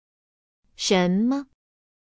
shénme